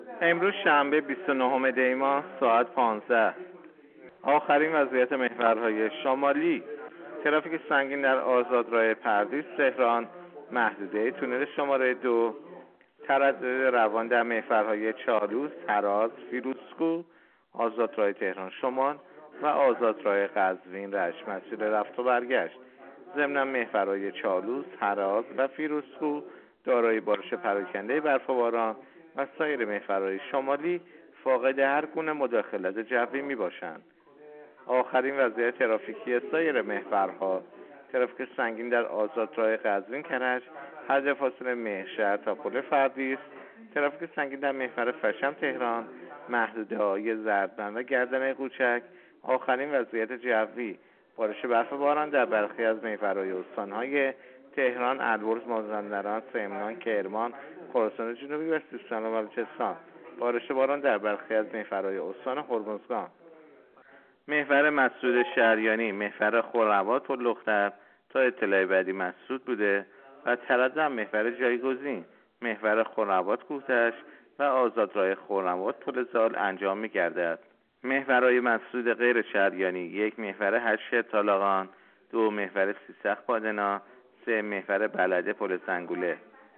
گزارش رادیو اینترنتی از آخرین وضعیت ترافیکی جاده‌ها تا ساعت ۱۵ بیست و نهم دی؛